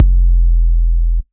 SouthSide Kick Edited (32).wav